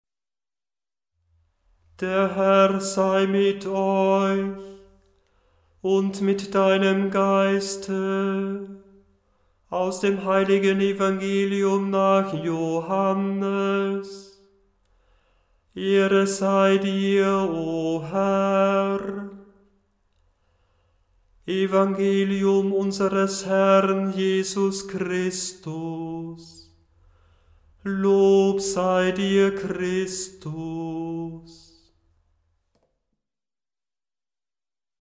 Die verschiedenen Einleitungen sind in der Tonhöhe absteigend geordnet,
Einleitung zum Evangelium nach Johannes 856 KB Erster Ton g, eine große Sekunde tiefer Einleitung zum Evangelium nach Johannes 893 KB Erster Ton f, eine große Terz tiefer Evangelium secundum Joanneum 904 KB Erster Ton a, latein Evangelium secundum Joanneum 931 KB Erster Ton g, latein Evangelium secundum Joanneum 982 KB Erster Ton f, latein
evg_johannes_dt_ton-g7.mp3